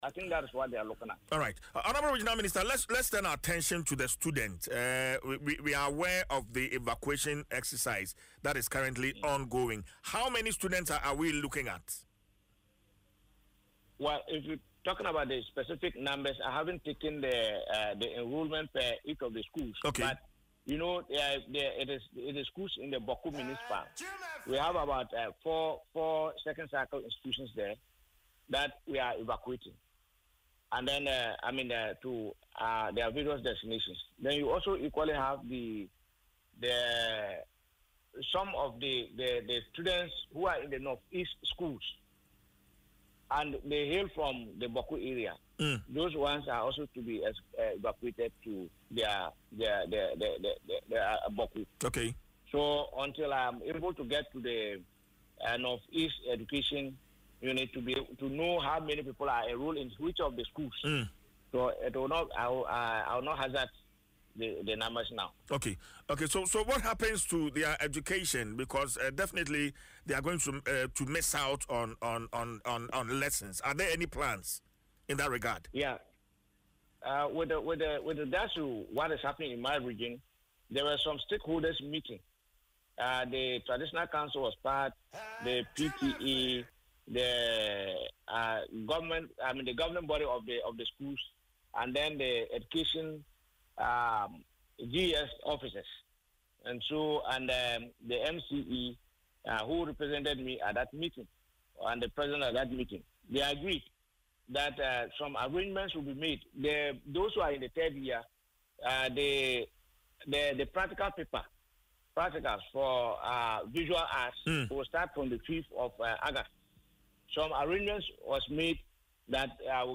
Speaking on Adom FM’s Dwaso Nsem, Mr. Akamugri said adequate security arrangements have been made to ensure the safety of both candidates and examination officials in the conflict-affected area.